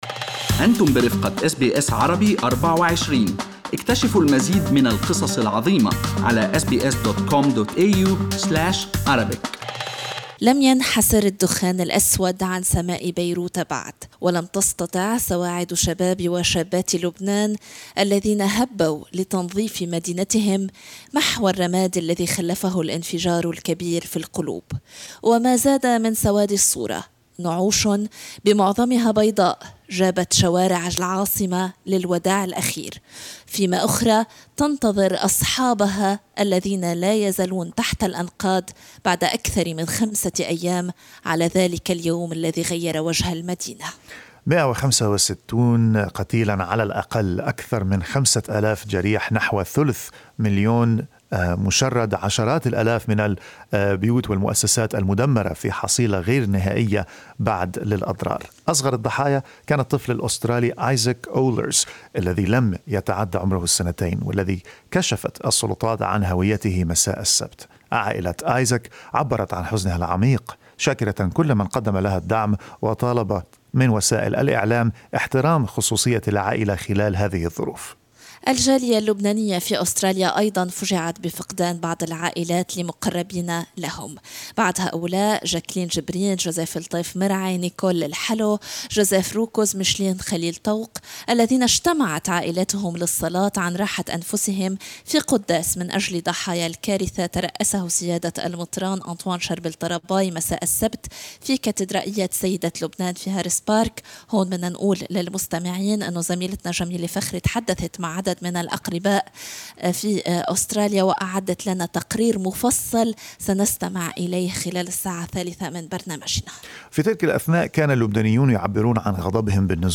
لقاء مع السفيرة اللبنانية للأردن (سابقاً) ترايسي شمعون حول الأسباب التي دفعت بها للاستقالة من منصبها يوم الخميس الماضي، بعد انفجار مرفأ بيروت.